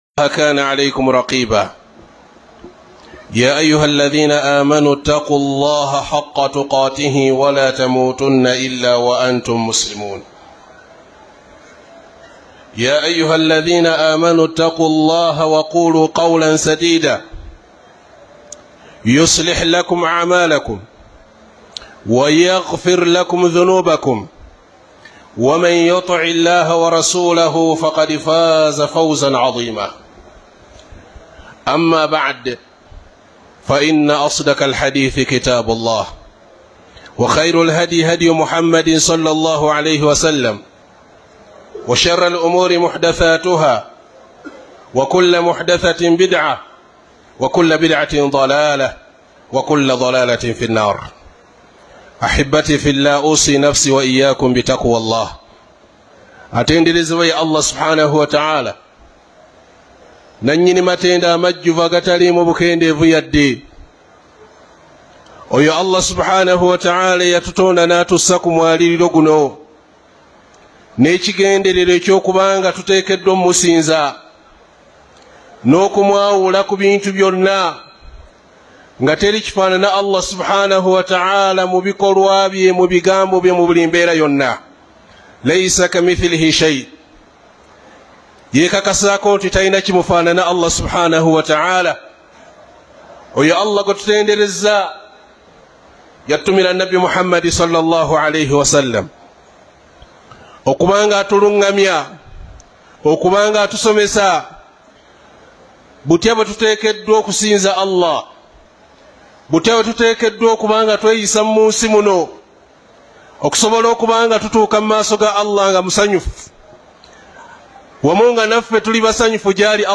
JUMMA KHUTUB